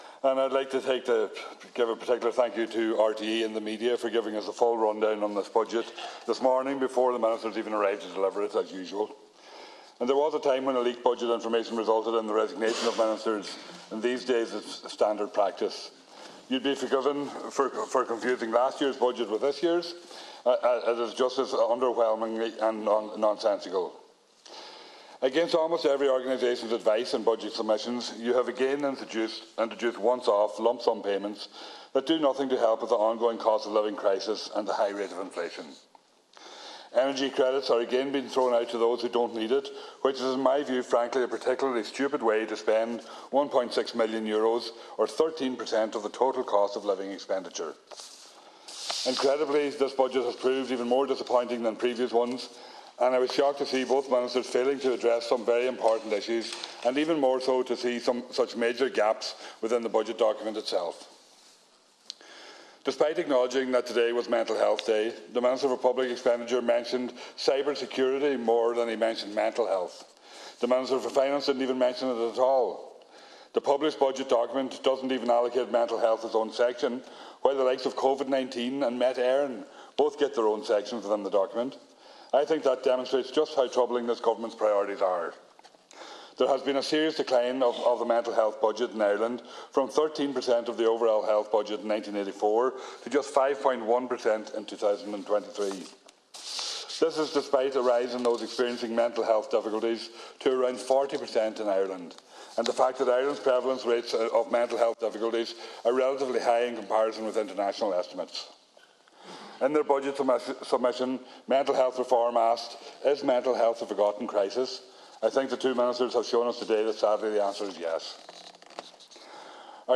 Deputy Thomas Pringle told the budget debate that the failure to support renters was a damning indictment of a government whose priorities were laid bare in this budget.